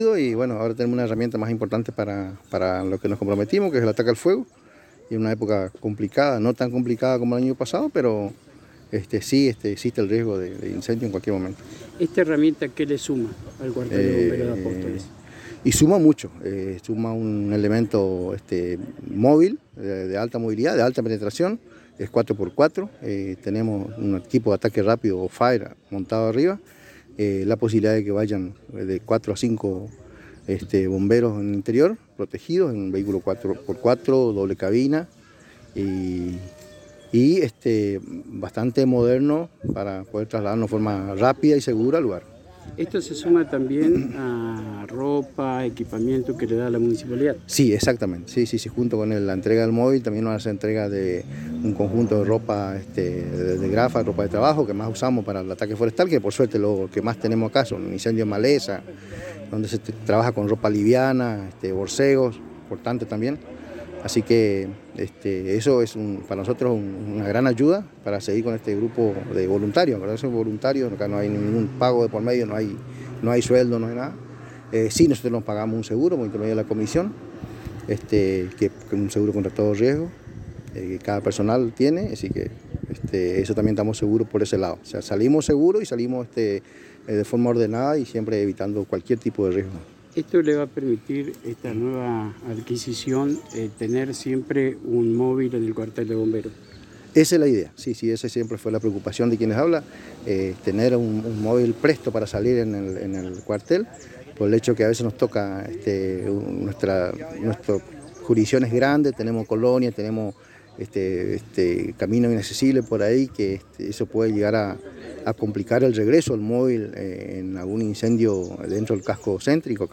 En esta oportunidad el secretario de Hacienda Javier Safrán en diálogo exclusivo con la ANG manifestó que en esta oportunidad se le provee al Cuerpo de Bomberos Voluntarios una camioneta cuatro por cuatro marcas Toyota con un tanque de agua de mil litros y bomba.